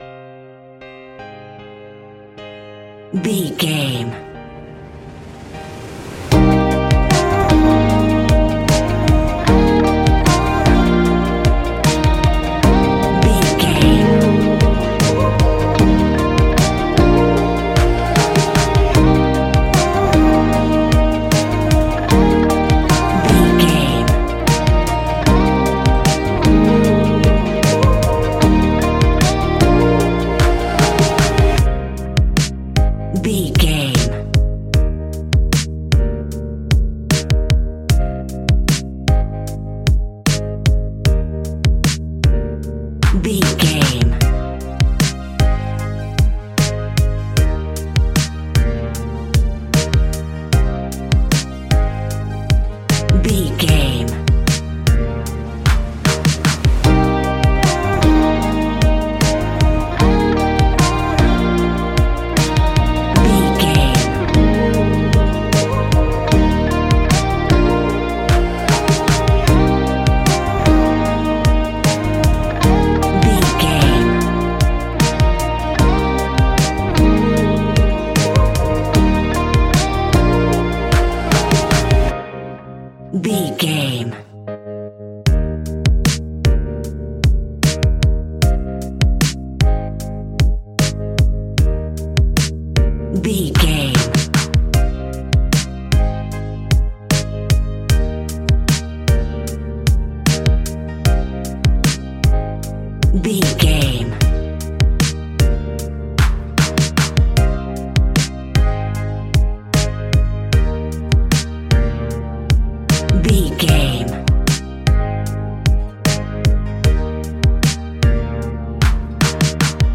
Ionian/Major
D♯
ambient
electronic
new age
downtempo
synth
pads